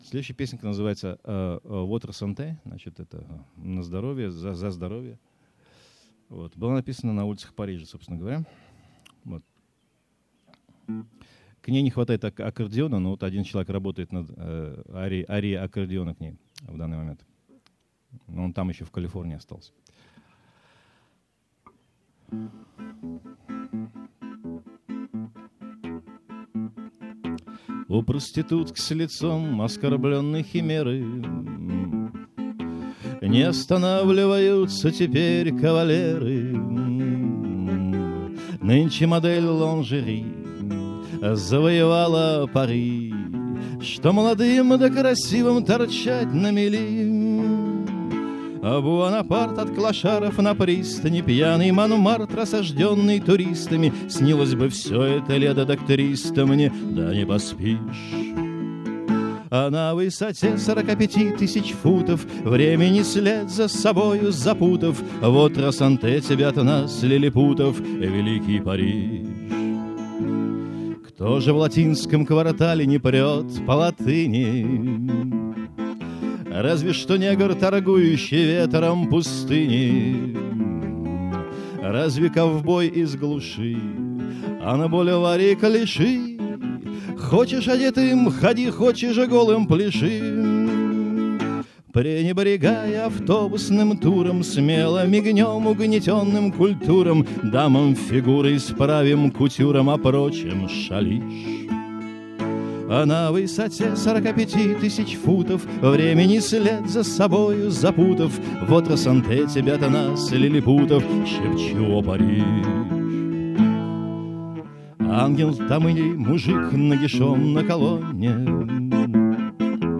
2005 - Граненый слёт